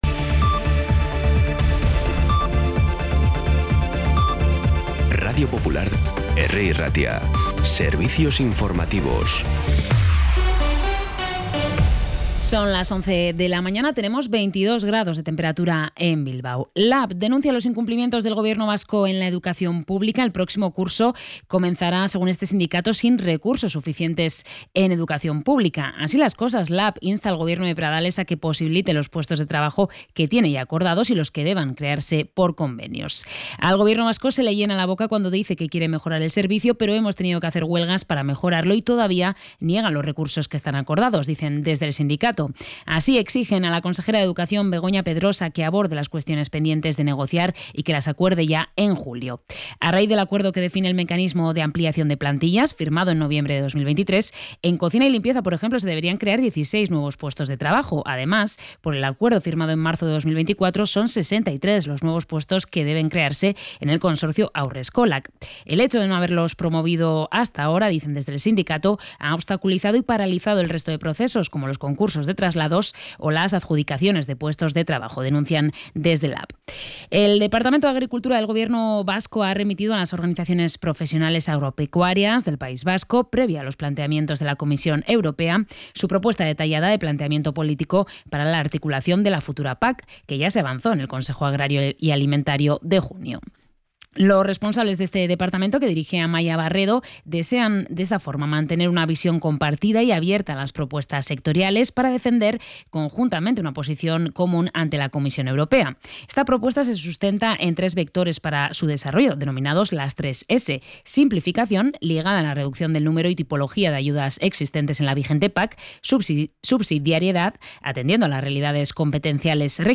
Información y actualidad desde las 11 h de la mañana